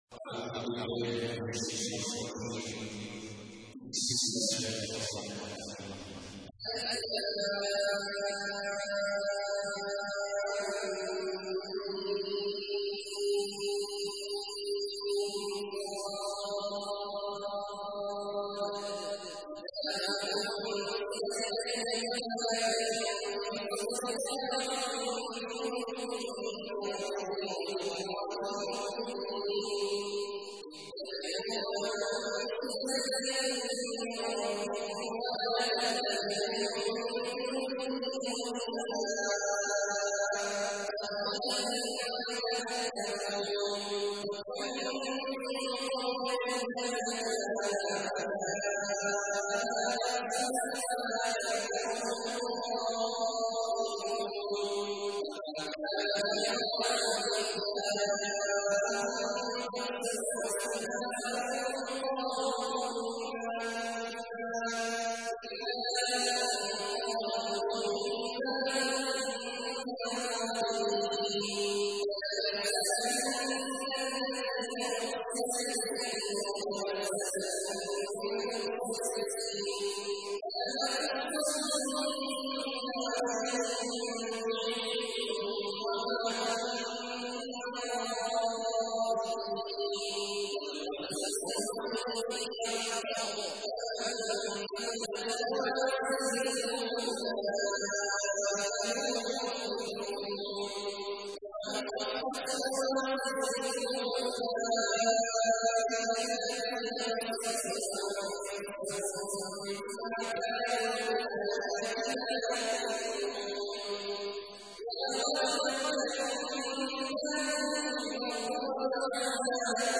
تحميل : 7. سورة الأعراف / القارئ عبد الله عواد الجهني / القرآن الكريم / موقع يا حسين